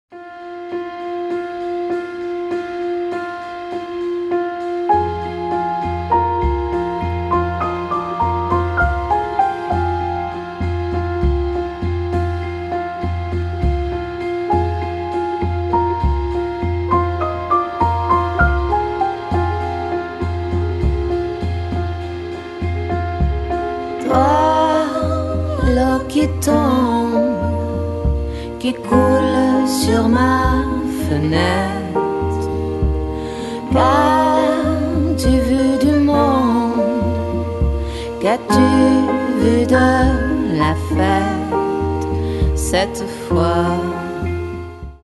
Genre : Variété française